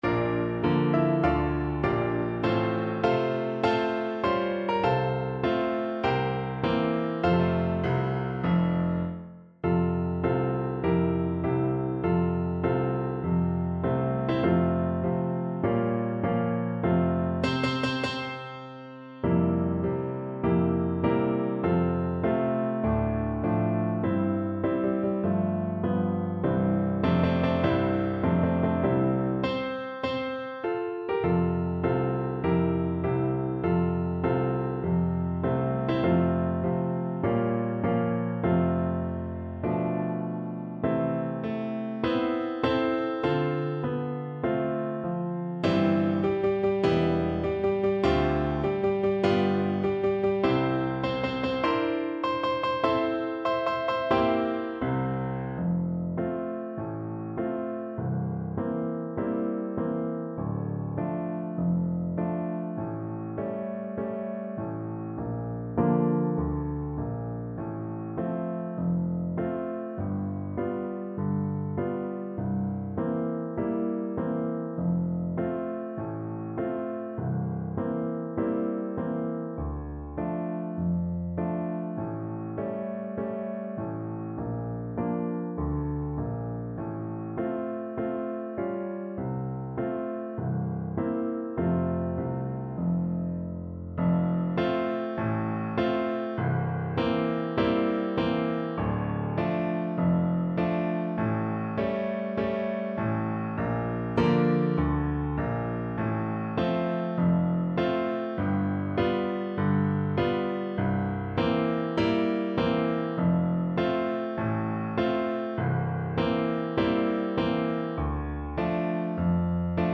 4/4 (View more 4/4 Music)
Tempo di Marcia
C4-E5
Traditional (View more Traditional French Horn Music)